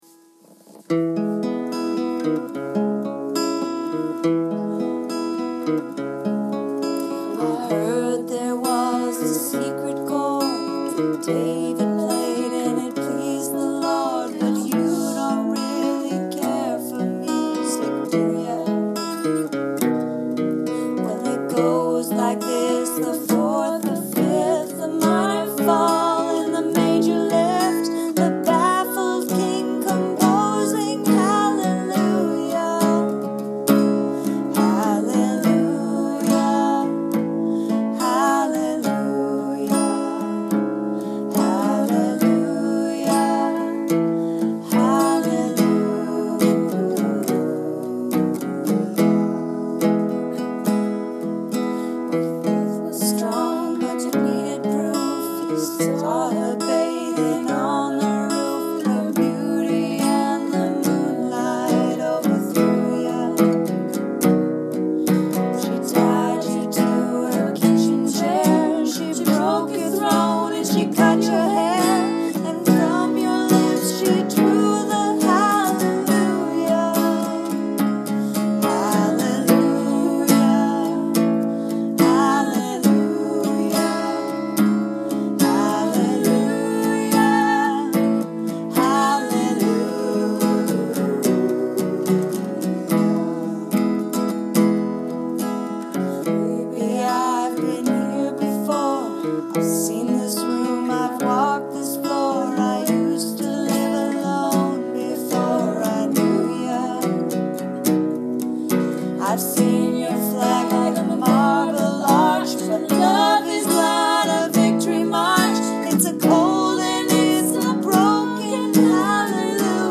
Recorded Christmas Eve, iPhone 6 audio.